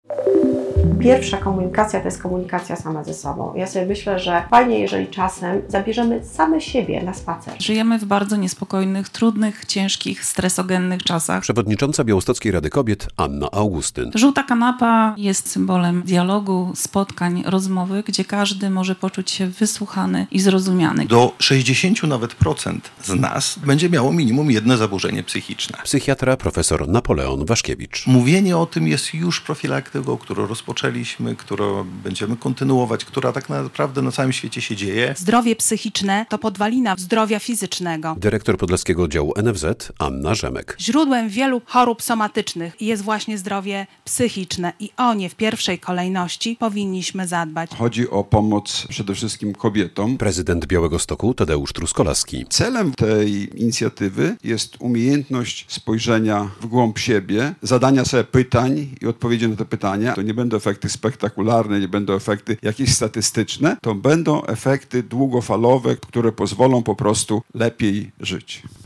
Rozpoczęła się kampania "Żółta Kanapa" na rzecz zdrowia psychicznego kobiet w Białymstoku - relacja